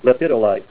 Help on Name Pronunciation: Name Pronunciation: Lepidolite + Pronunciation
Say LEPIDOLITE Help on Synonym: Synonym: Lithium Mica